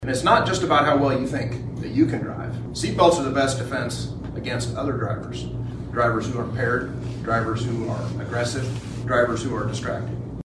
The Kansas Department of Transportation officially launched the statewide “Click It or Ticket” campaign Wednesday at Manhattan’s Peace Memorial Auditorium.
Kansas Highway Patrol Superintendent Col. Erik Smith says seat belts are your best defense against other drivers, who may be impaired, aggressive or distracted on the roadways